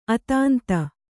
♪ atānta